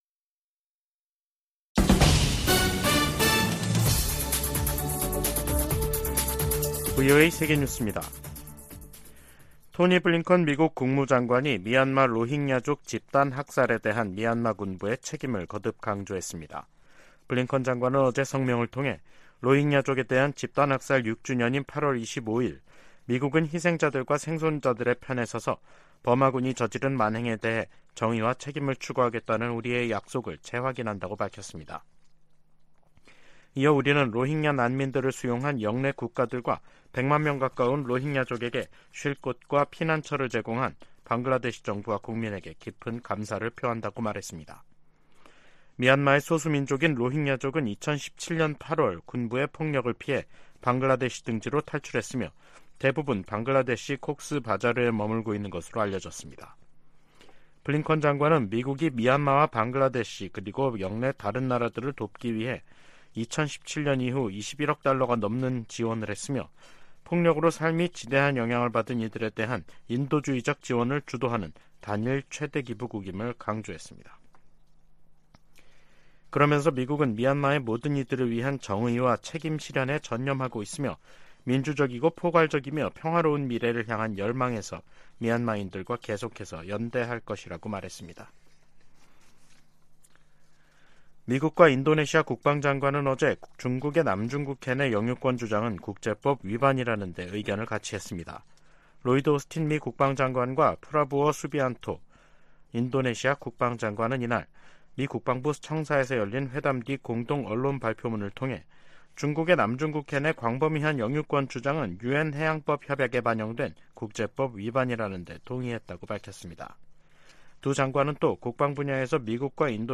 VOA 한국어 간판 뉴스 프로그램 '뉴스 투데이', 2023년 8월 25일 3부 방송입니다. 유엔 안전보장이사회가 25일 미국 등의 요청으로 북한의 위성 발사에 대한 대응 방안을 논의하는 공개 회의를 개최합니다. 미 국방부가 북한의 2차 정찰위성 발사를 비판하며 지역의 불안정을 초래한다고 지적했습니다. 북한 해킹조직이 탈취한 거액의 암호화폐를 자금세탁해 현금화할 가능성이 있다고 미국 연방수사국(FBI)이 경고했습니다.